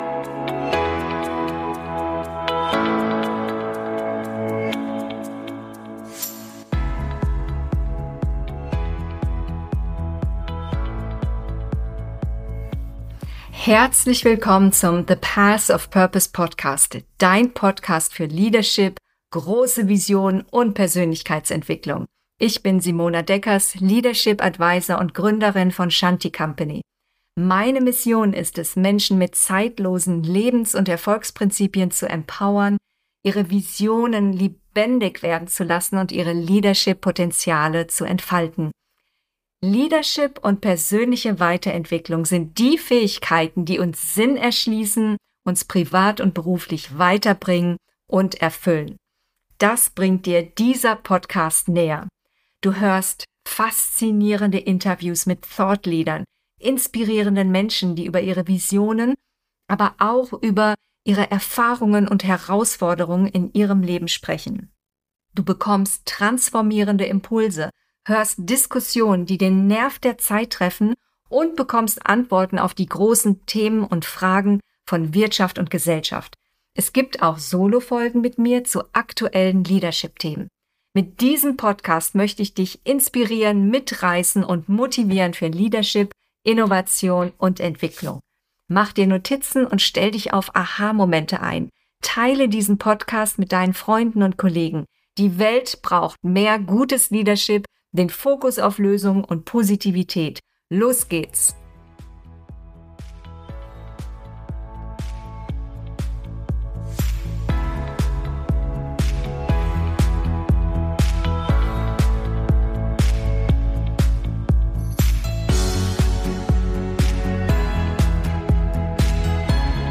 Energie Management und echte Erholung - Interview